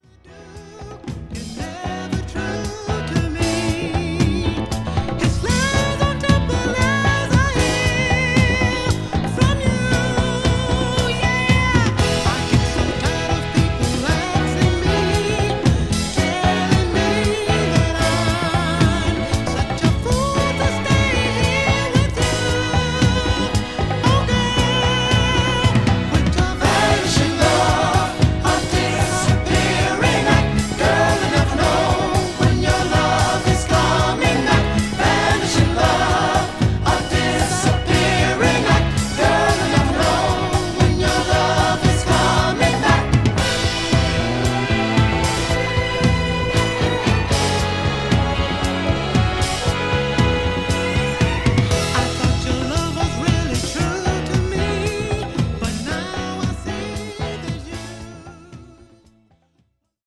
ホーム ｜ SOUL / FUNK / RARE GROOVE / DISCO > SOUL